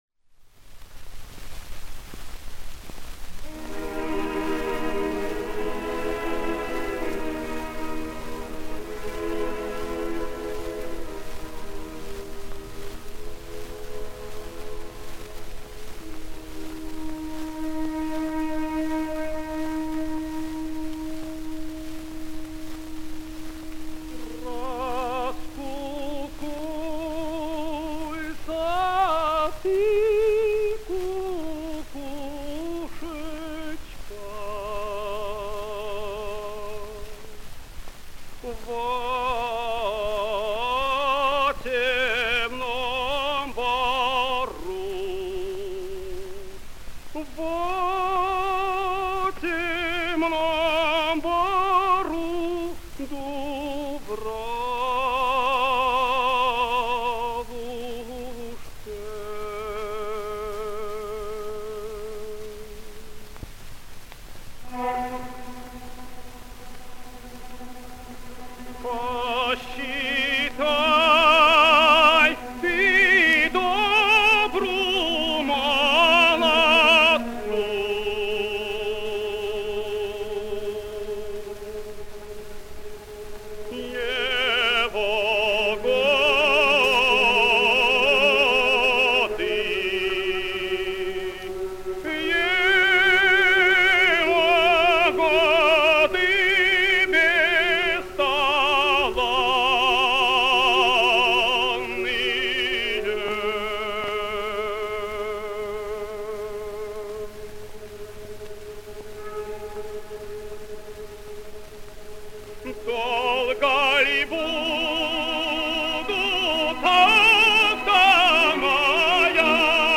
Песня Михайло Тучи. Оркестр ГАБТ СССР. Дирижёр В. Л. Кубацкий. Исполняет С. Н. Стрельцов.
Обладал сильным лирико-драматическим тенором широкого диапазона, мягкого тембра с характерной окраской, драматическим и комедийным талантом.